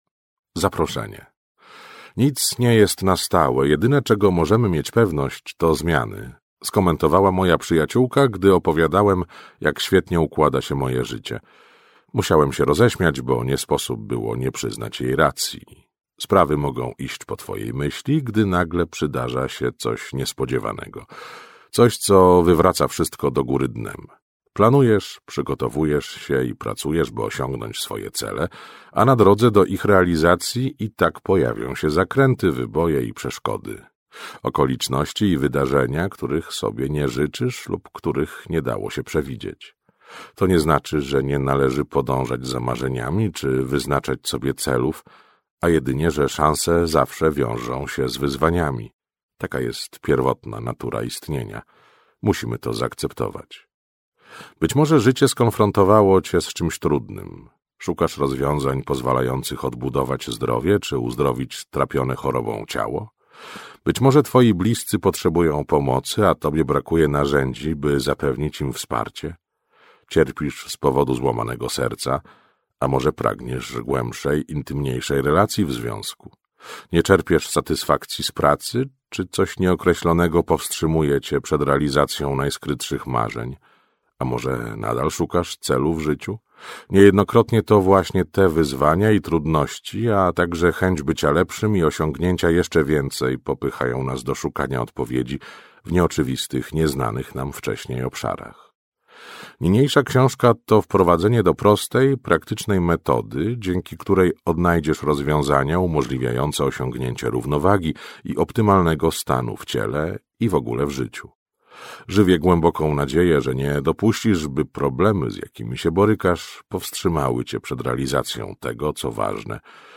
fragment książki: